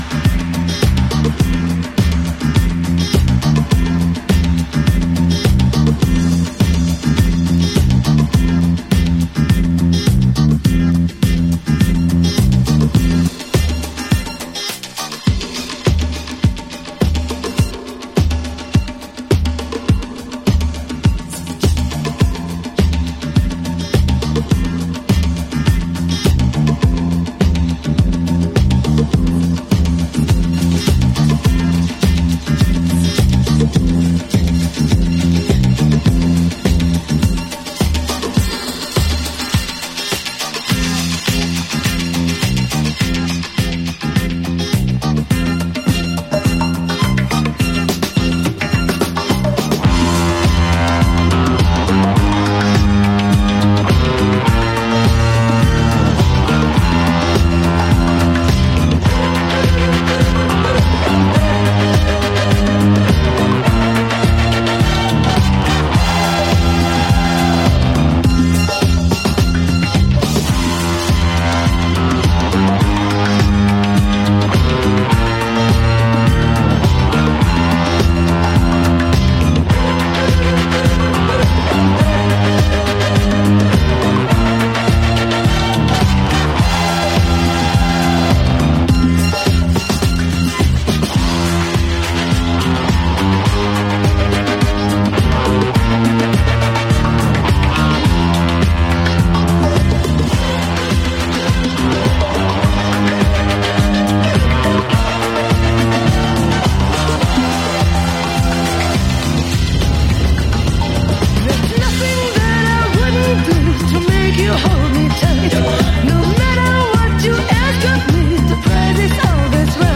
Disco House Funk